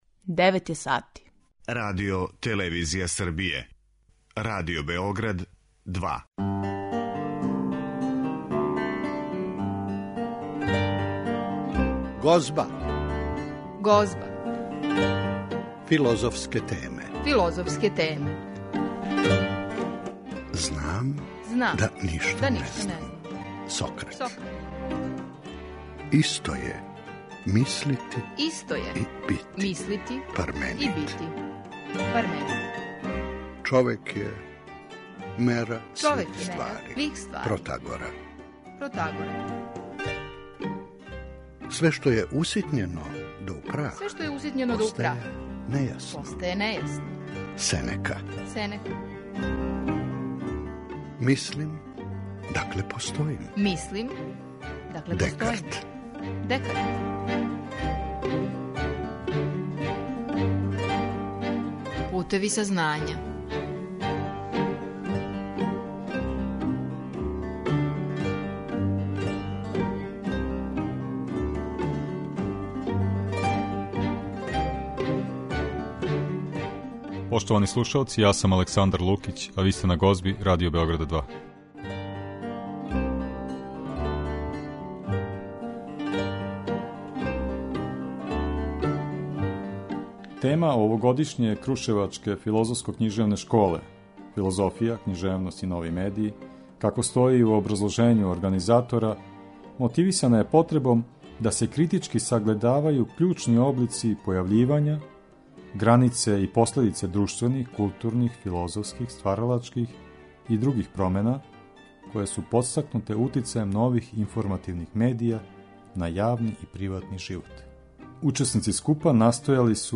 а чућемо и делове излагања неких учесника школе.